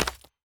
Chopping and Mining
mine 4.wav